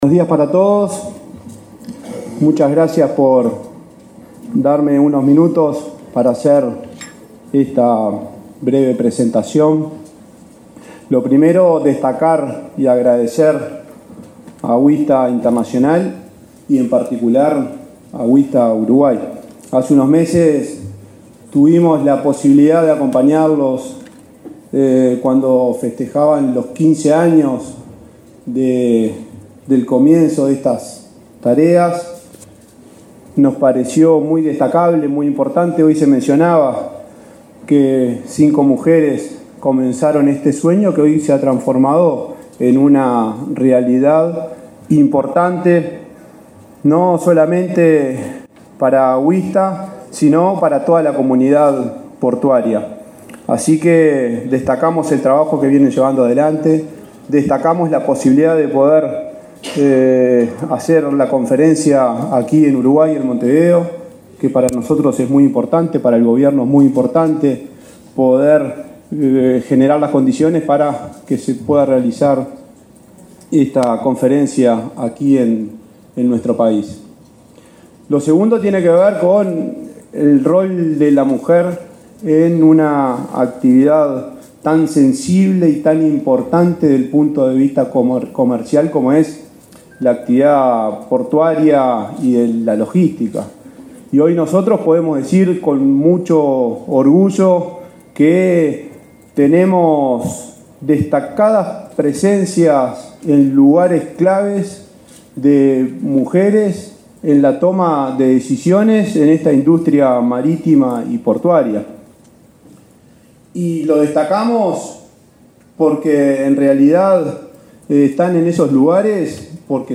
Palabras del presidente de la ANP, Juan Curbelo
Palabras del presidente de la ANP, Juan Curbelo 26/10/2023 Compartir Facebook X Copiar enlace WhatsApp LinkedIn El presidente de la Administración Nacional de Puertos (ANP), Juan Curbelo, participó, este jueves 26 en Montevideo, en la asamblea general y conferencia de Asociación Internacional de Mujeres del Comercio y Transporte Marítimo (Wista, por sus siglas en inglés).